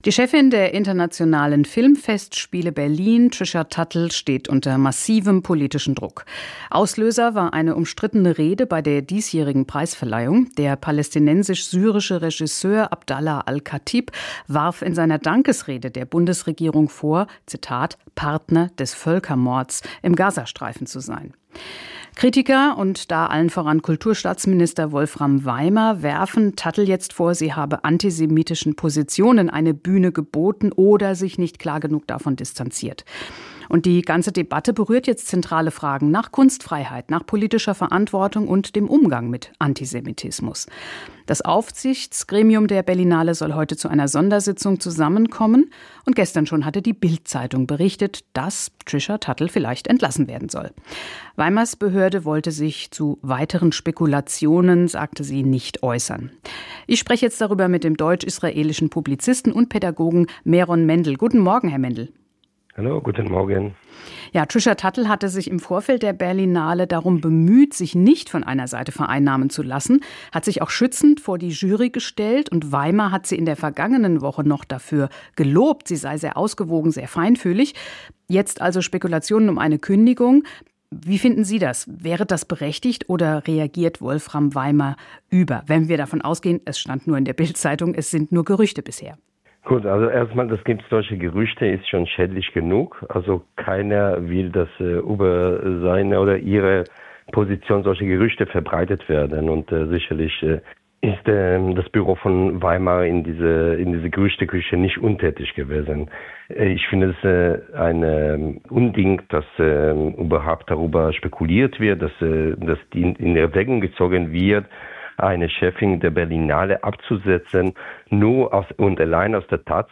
Direktor der Bildungsstätte Anne Frank im Gespräch
Interview mit